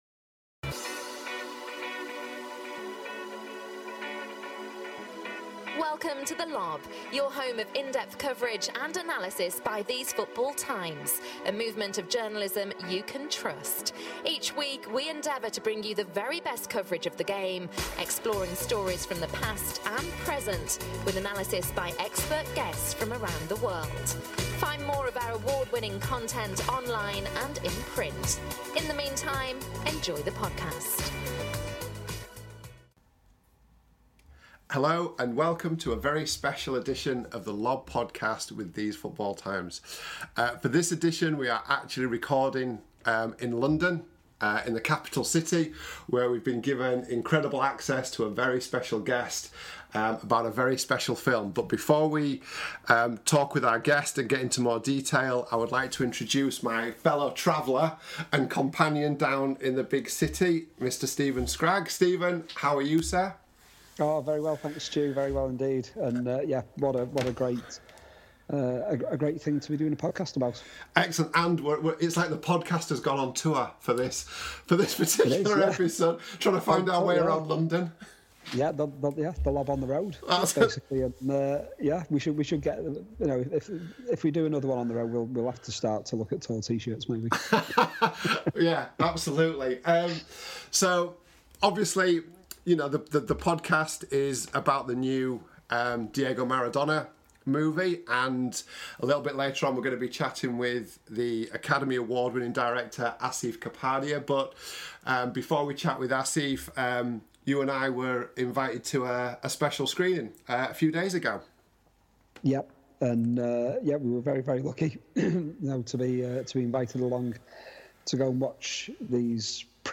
The Maradona movie: an exclusive interview with director Asif Kapadia